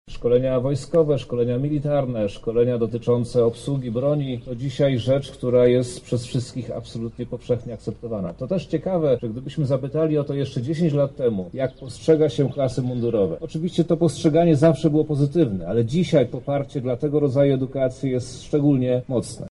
-mówi minister edukacji i nauki Przemysław Czarnek.